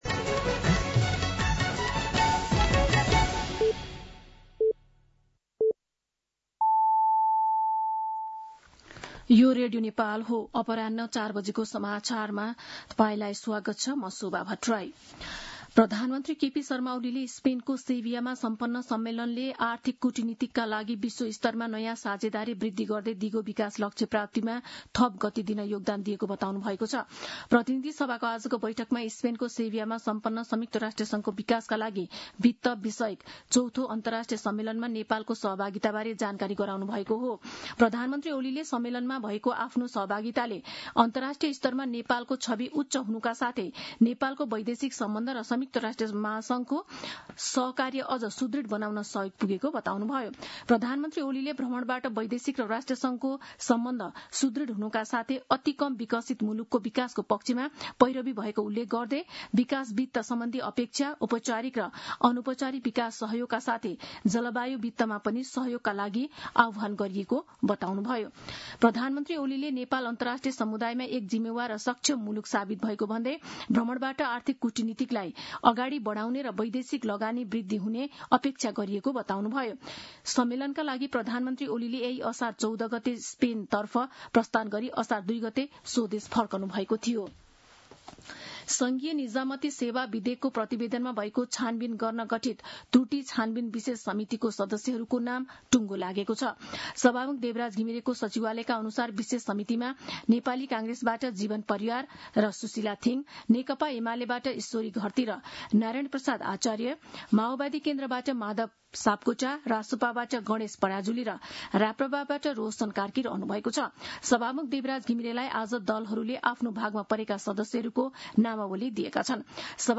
दिउँसो ४ बजेको नेपाली समाचार : २३ असार , २०८२